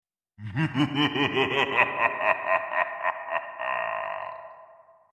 Evil Scary Laugh